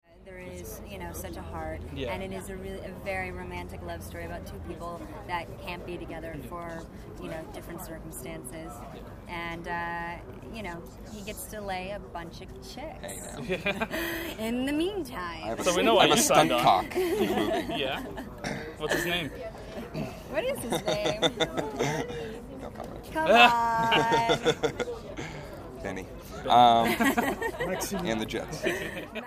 It turned into a 3 ½ minute interview done just before they jumped into the press line. So, call it an extended press-line interview or a warm-up, if you will.